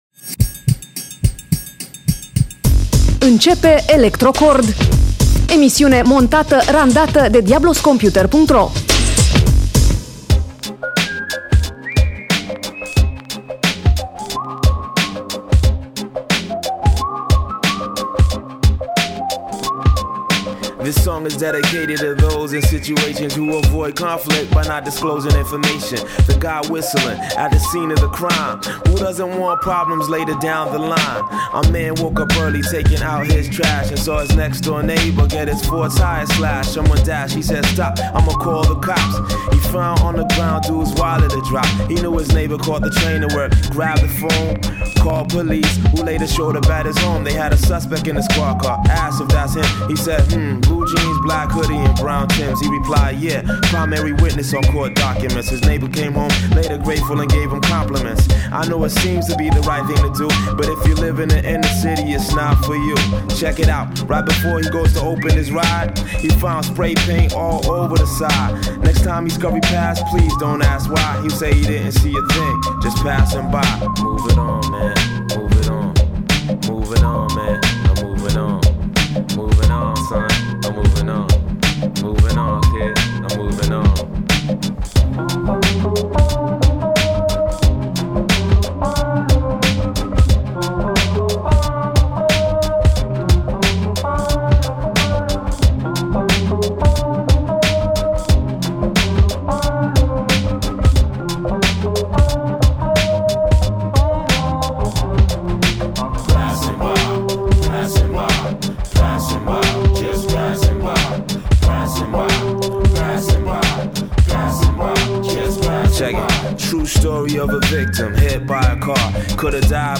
dedicată muzicii electronice de dans (EDM)
un mix de piese numai potrivite pentru începutul de weekend